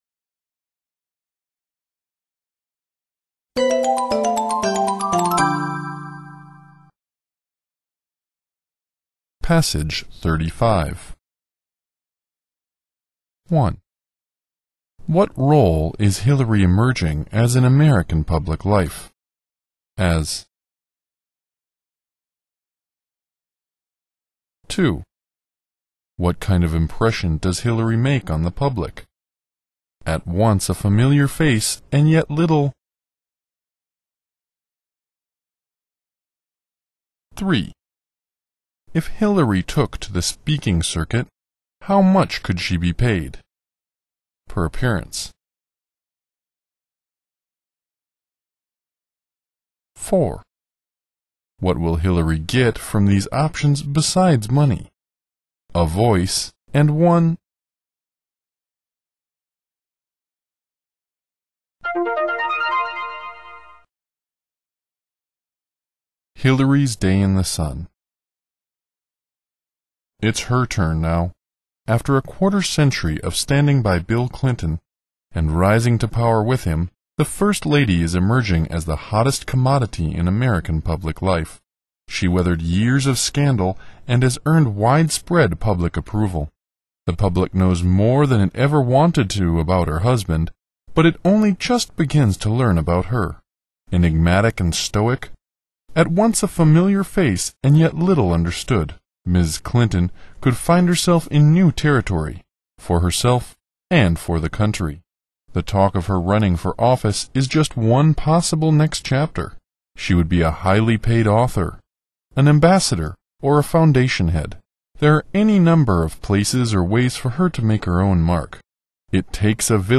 常考时文朗诵35 听力文件下载—在线英语听力室